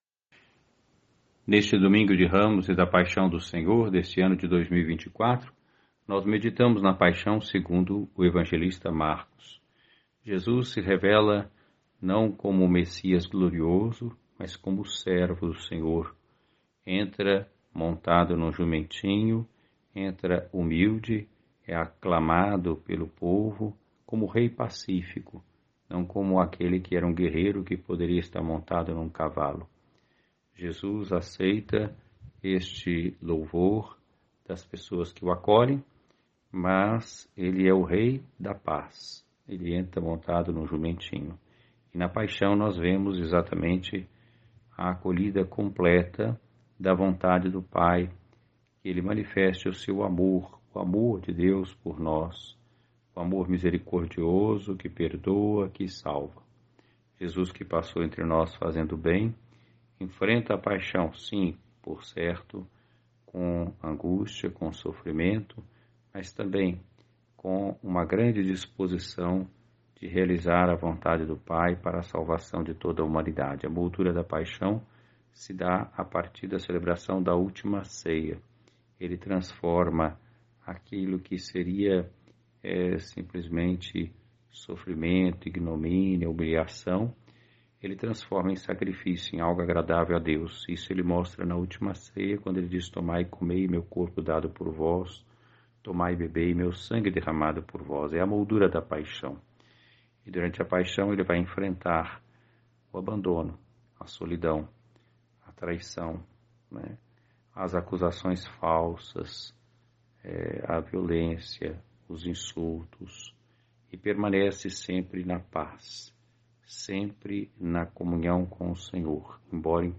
O bispo diocesano, Dom Lauro Sérgio Versiani Barbosa, presidiu a Santa Missa, cuja homilia destacou a entrada triunfante e, ao mesmo tempo, humilde, de Jesus Cristo em Jerusalém, montado num singelo burrinho. Ele concedeu à nossa reportagem a gentileza de gravar um áudio, resumindo um trecho de sua pregação.